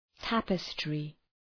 {‘tæpıstrı}